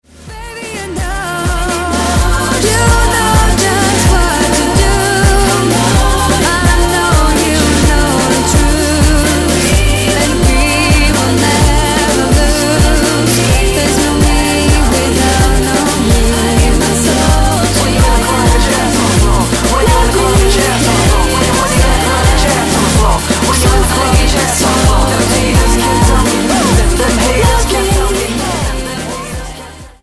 W/ Vocals